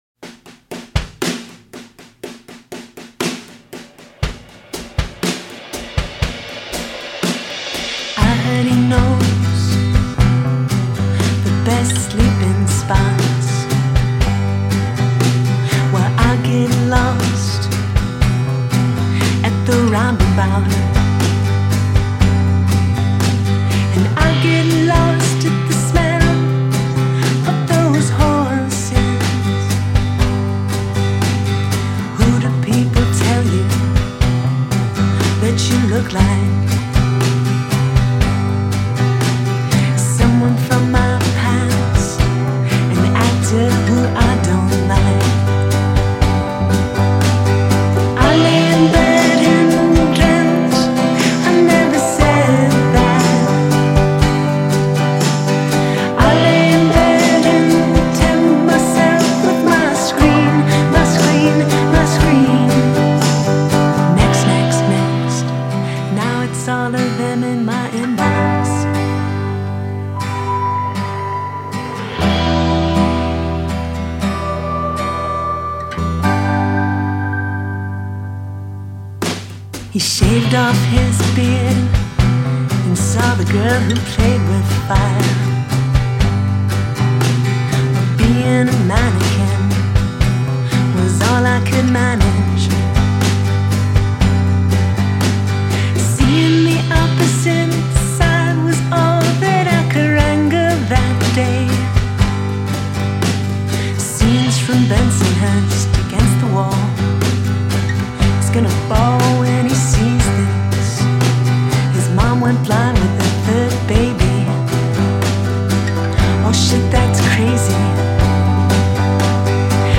la cantante di Chicago